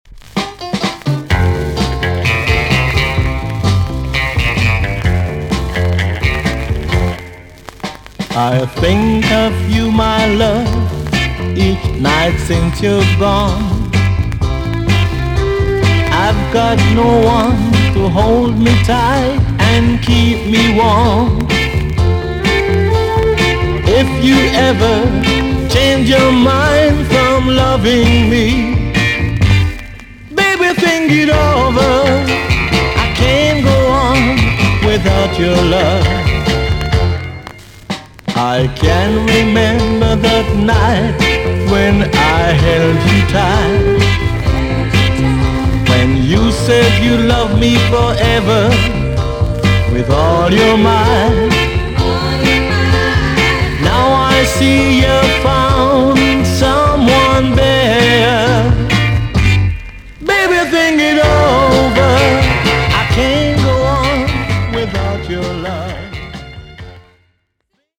TOP >REGGAE & ROOTS
VG+ 軽いチリノイズがあります。